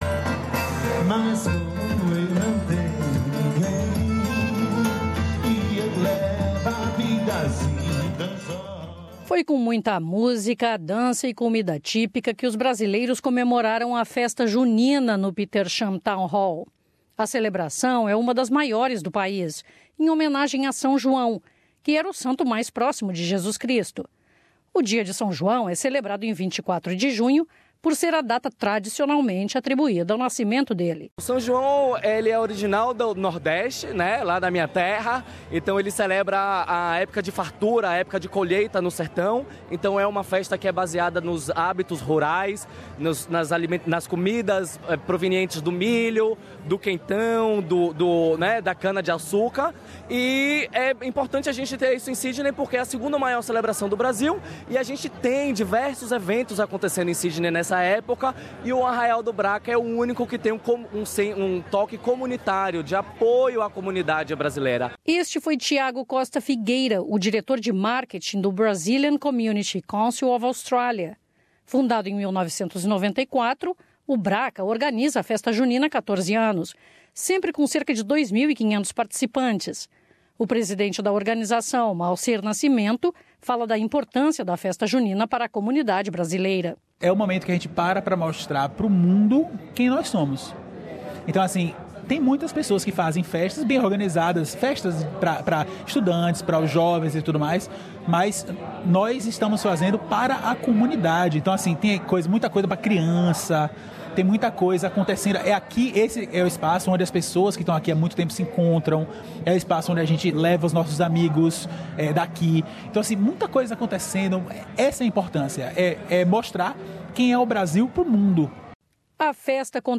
O "Arraial do Bracca" foi um evento animado que teve muita música, dança e comida típica.